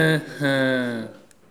hein-hein_01.wav